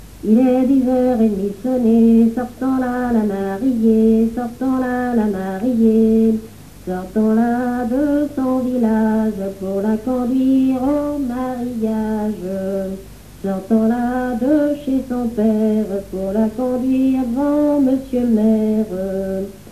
circonstance : fiançaille, noce
Genre énumérative
Pièce musicale inédite